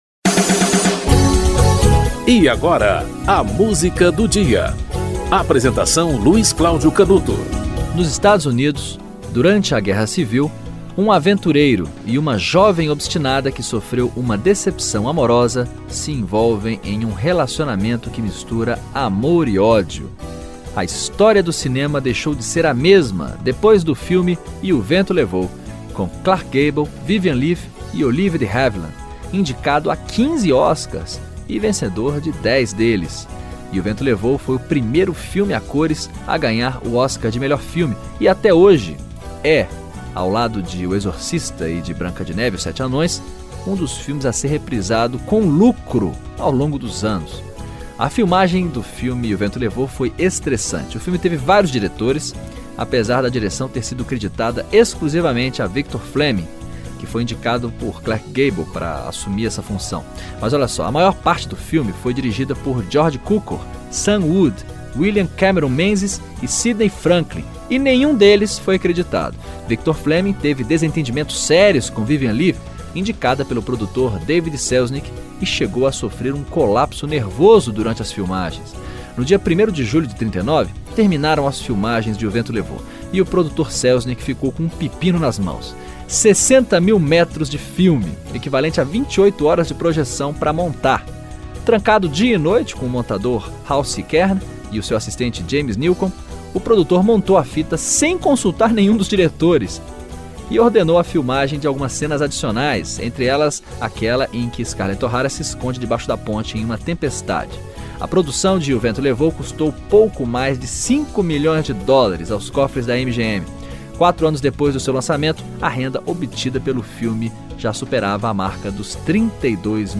Orquestra da Warner Brothers - Gone With The Wind - (Max Steiner)